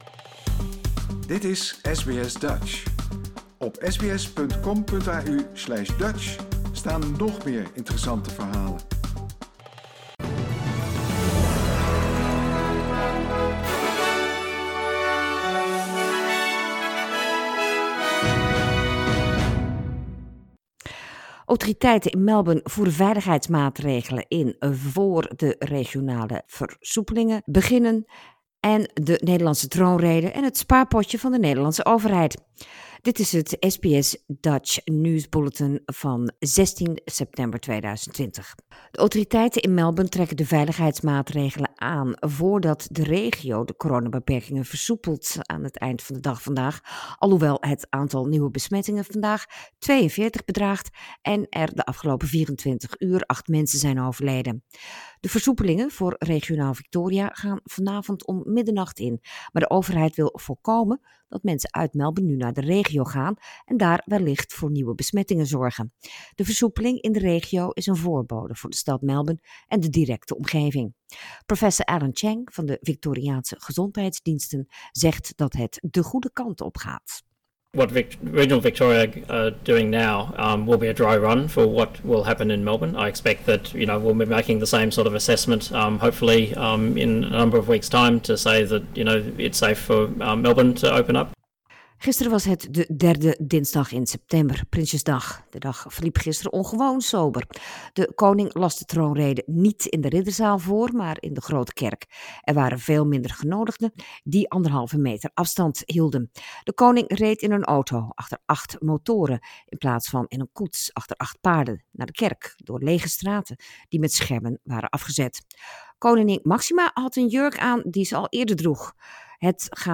Nederlands/Australisch SBS Dutch nieuwsbulletin woensdag 16 september 2020i